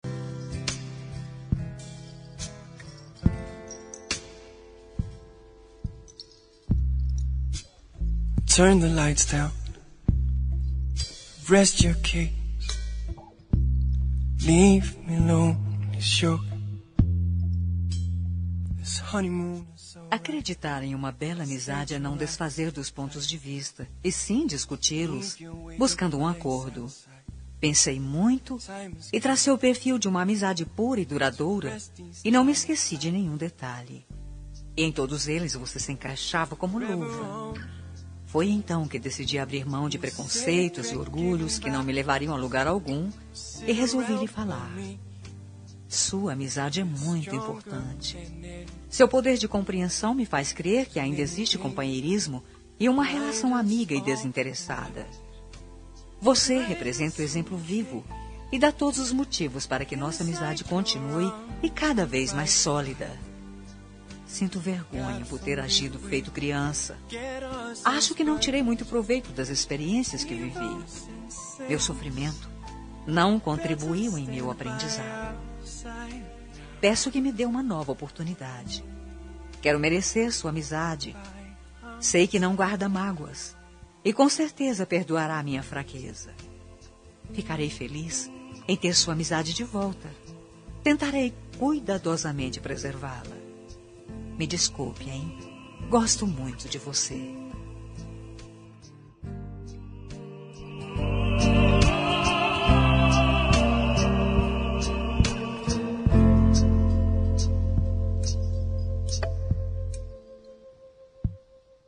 Reconciliação Amizade – Voz Feminina – Cód: 036795